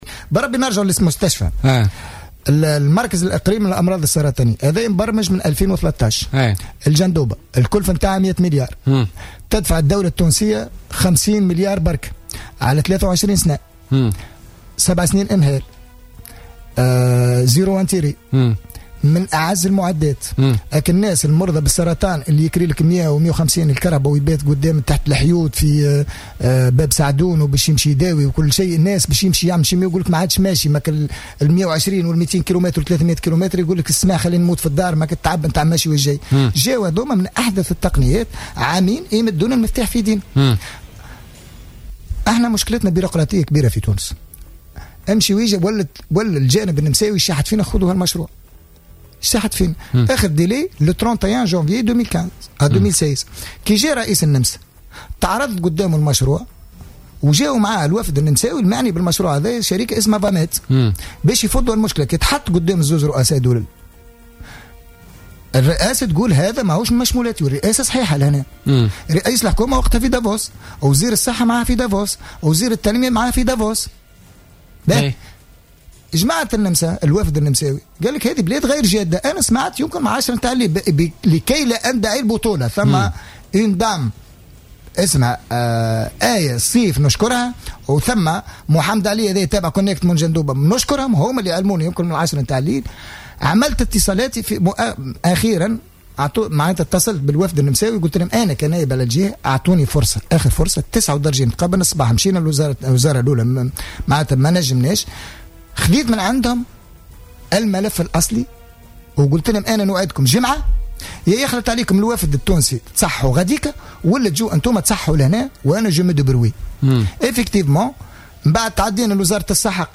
Invité de l'émission Politica, le député, Fayçal Tebbini, a évoqué ce mercredi le projet du centre local pour le traitement du cancer prévu au gouvernorat de Jendouba.